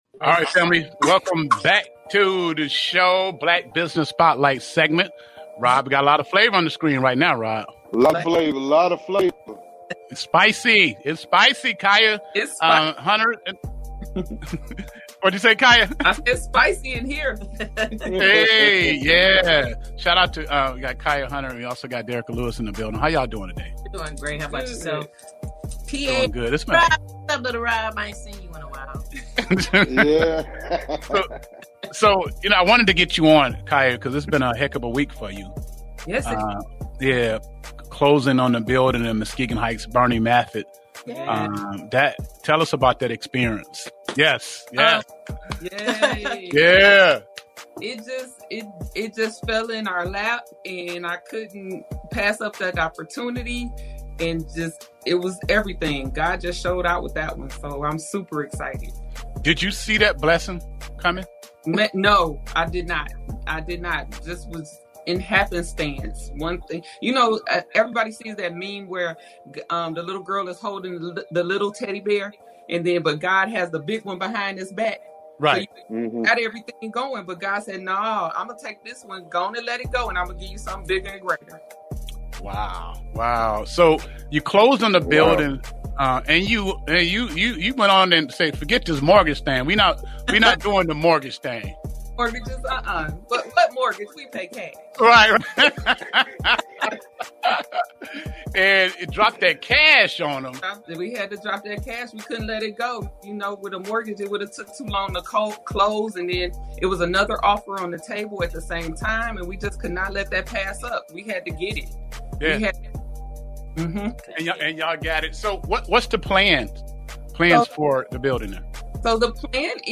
WUVS 103.7 The Beat / Interview